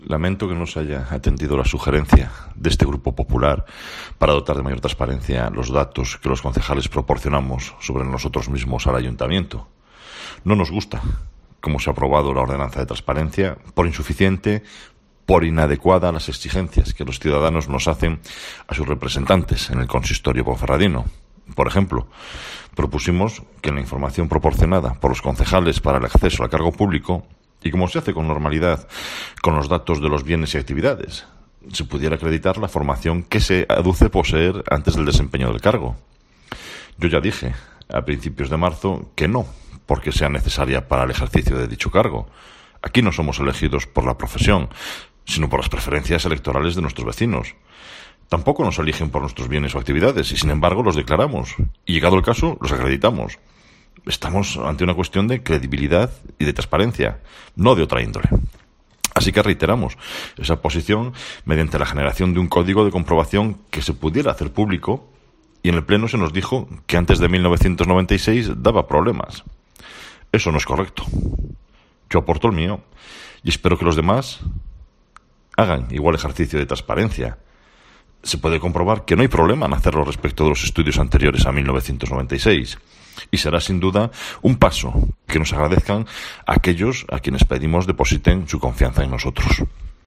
Escucha aquí las palabras de Marco Morala, portavoz popular en la capital berciana